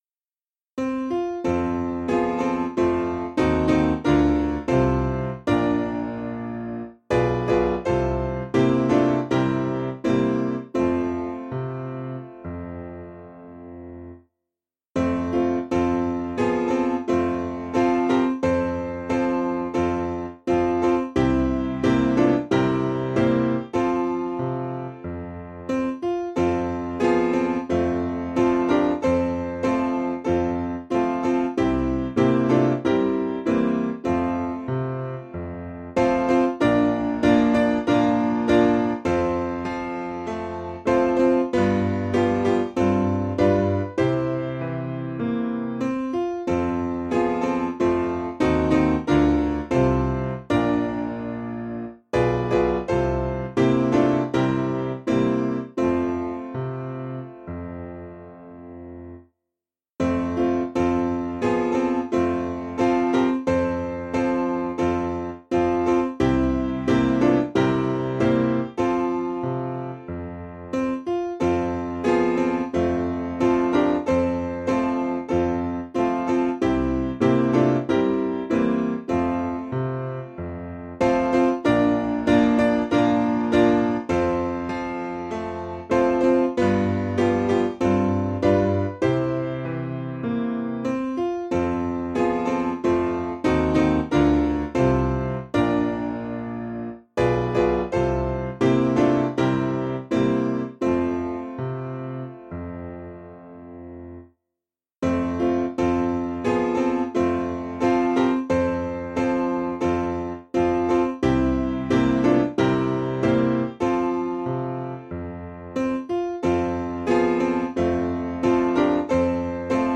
Meter: 11.8.11.8 with refrain
Key: F Major